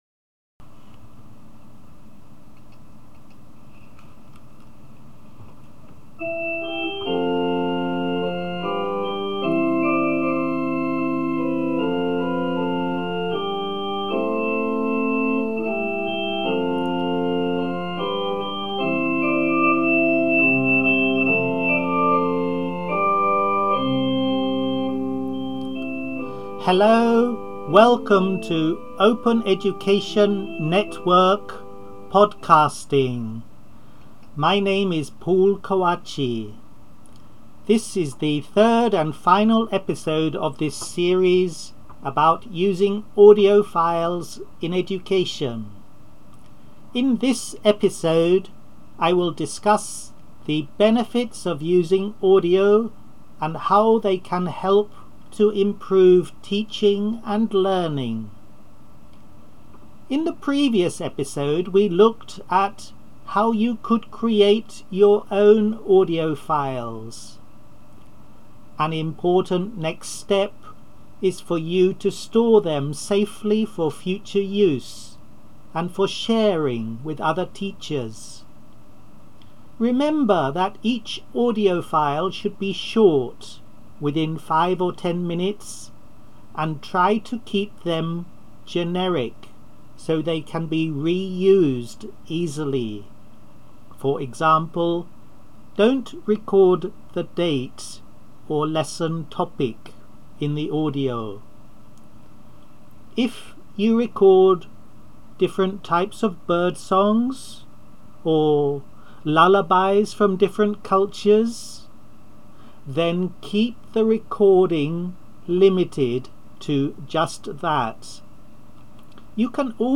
Moreover, my short fade-in / fade-out theme music was created by me, as were my graphics - both copyrighted by me.